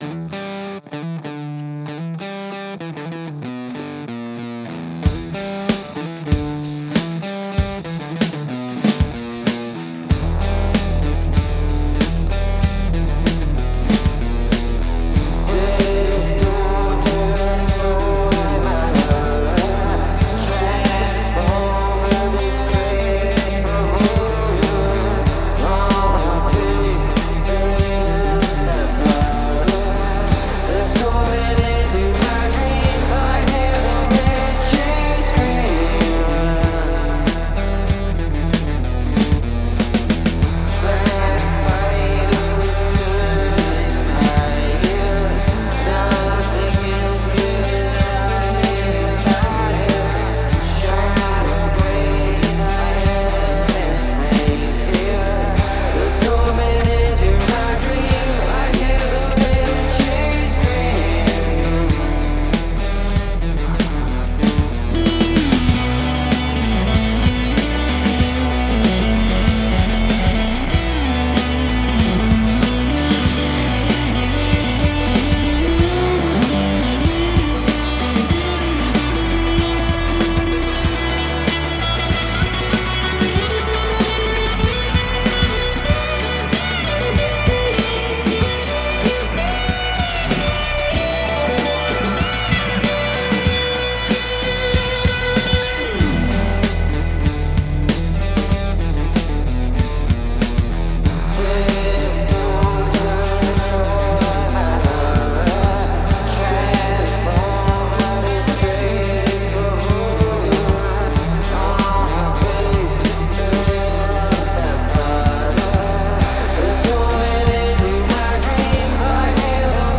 Must use the voice or mouth sounds as an instrument (other than vocals)
And that was an excellent solo! It does need a decent coda.